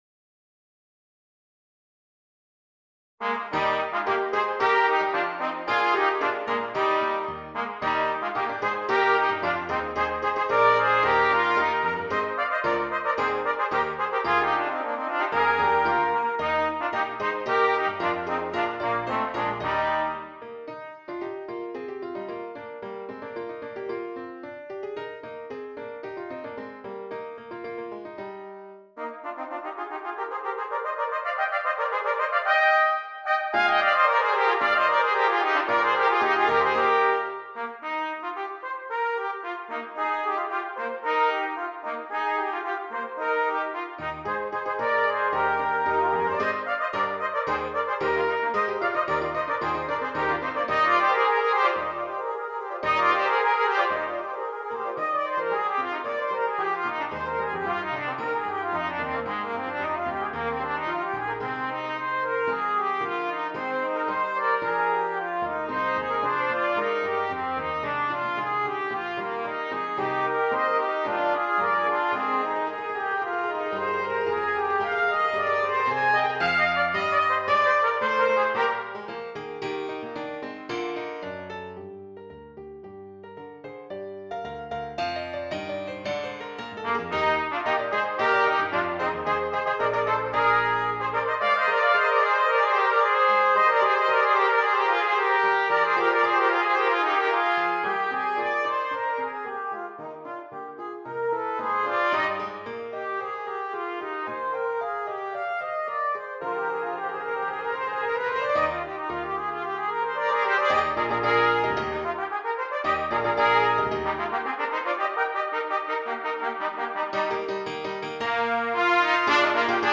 2 Trumpets and Keyboard